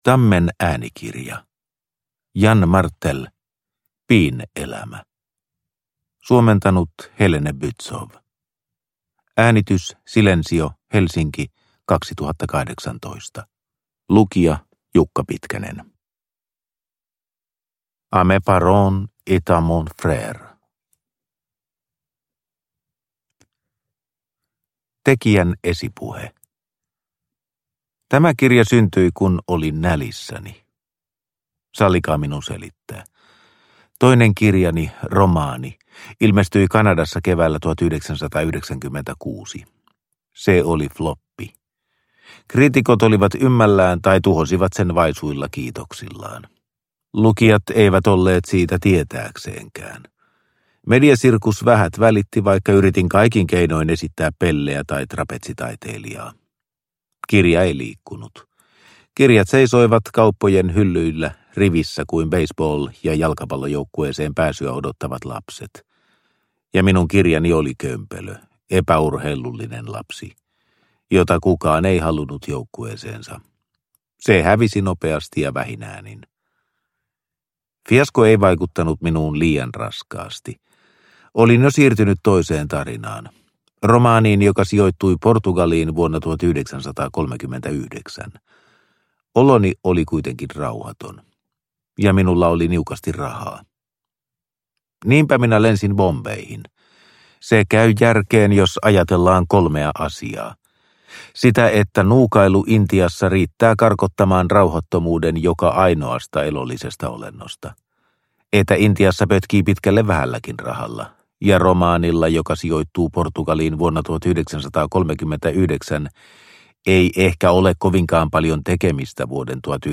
Piin elämä – Ljudbok – Laddas ner